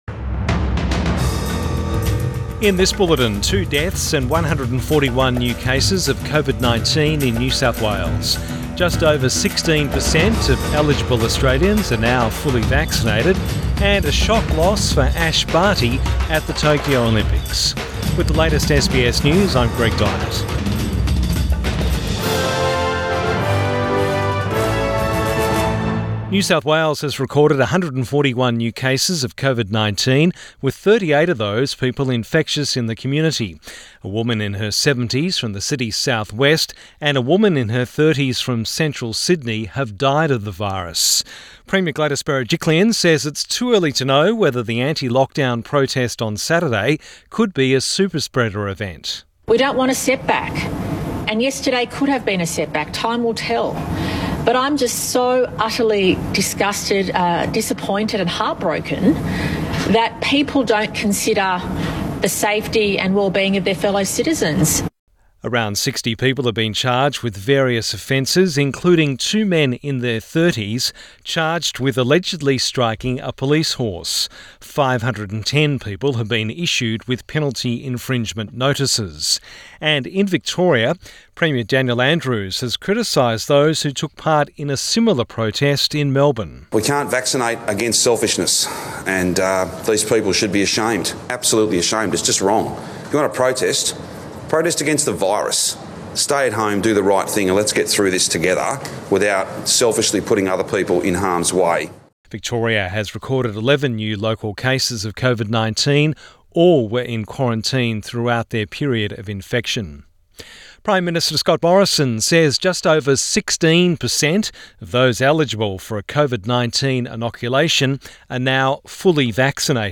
PM bulletin 25 July 2021